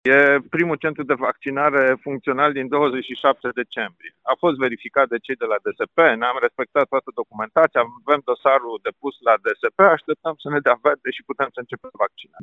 La Giroc și la Dumbrăvița, sunt pregătite pentru etapa a doua de imunizare anticovid. Se așteaptă doar aprobarea Direcției de Sănătate Publică, potrivit primarului din Dumbrăvița, Horia Bugărin.